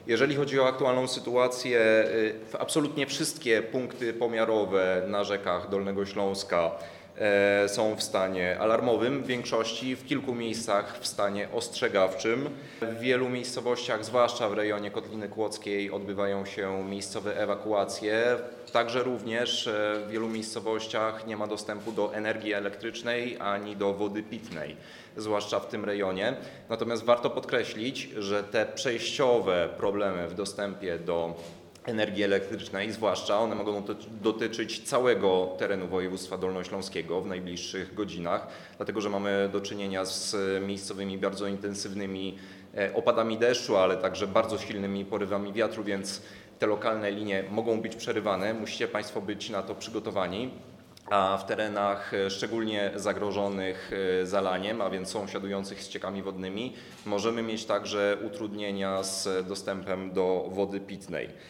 Raport dotyczący sytuacji na Dolnym Śląsku przedstawił w niedzielę o 12:30 wicewojewoda dolnośląski – Piotr Kozdrowicki.